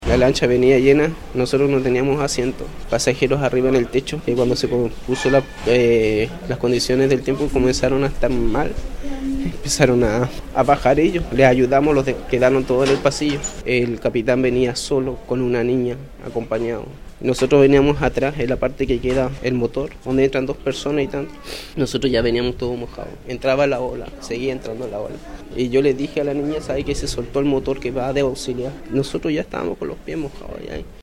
Uno de los sobrevivientes de la tragedia entregó su testimonio, señalando que “la lancha venía llena. Nosotros no tenemos asientos, pasajeros arriba en el techo, y cuando las condiciones del tiempo comenzaron a estar mal, empezaron a bajar ellos”.